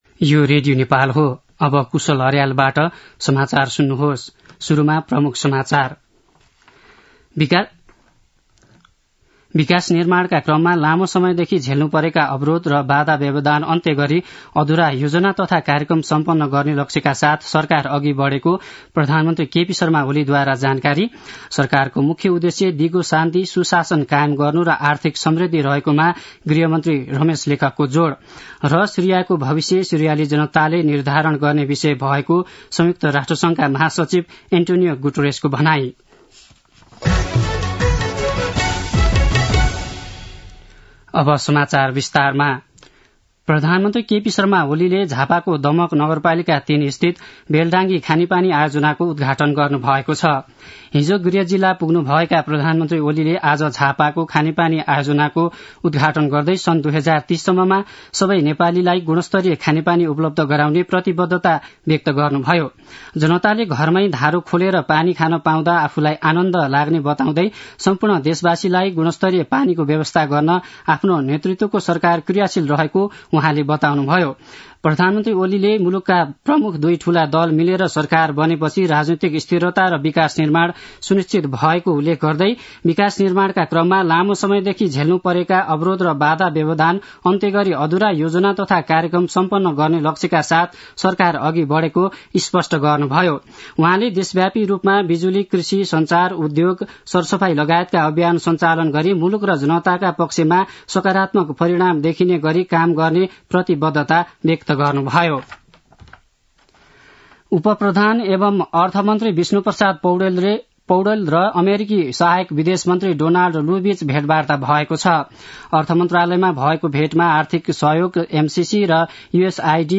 दिउँसो ३ बजेको नेपाली समाचार : २५ मंसिर , २०८१
3-pm-nepali-news-1-6.mp3